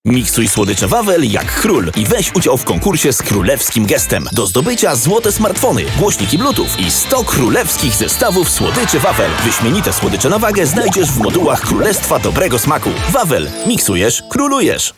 Kommerziell, Tief, Natürlich, Zuverlässig, Freundlich
Kommerziell
Flexible, energetic and charismatic voice.